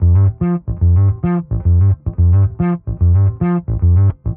Index of /musicradar/dusty-funk-samples/Bass/110bpm
DF_PegBass_110-G.wav